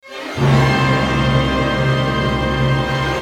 DM PAD6-5.wav